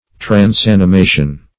Transanimation \Trans*an`i*ma"tion\
(tr[a^]ns*[a^]n`[i^]*m[=a]"sh[u^]n), n. [Cf. F.